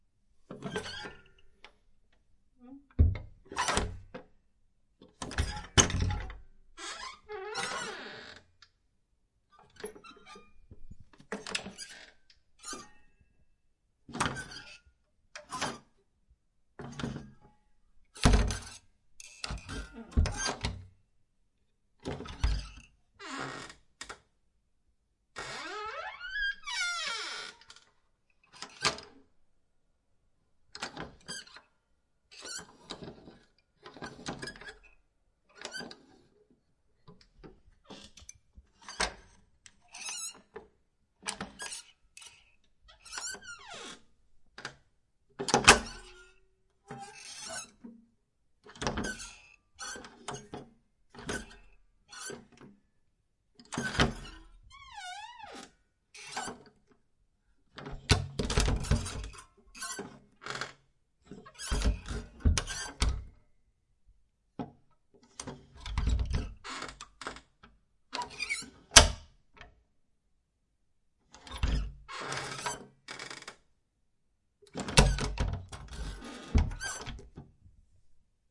大不列颠 " 教堂门关闭 1
描述：在一个老教会里面的门关闭。螺栓的声音。
我自己用ZOOM H4录制。
标签： 音景 关闭 关闭 氛围 教堂 螺栓 金属 现场记录
声道立体声